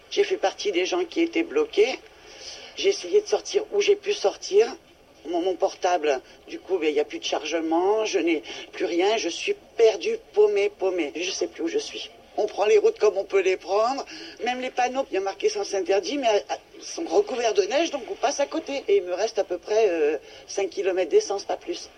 En voici un très court, d’une conductrice qui ne sait plus quelle direction prendre, une fois qu’elle ne peut plus suivre son itinéraire habituel.
3. paumé = perdu. (style familier et oral)